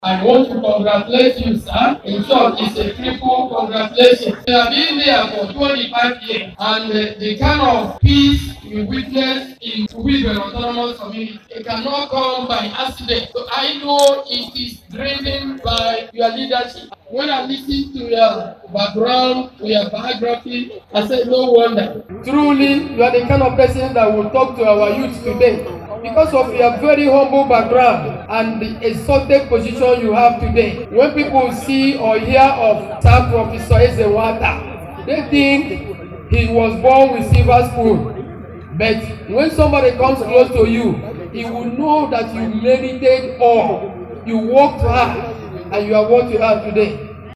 Earlier, Governor Alex Otti represented by the Mayor of Ikwuano Anthony Nwaubani lauded the monarch stressing that he attained his present status on merit.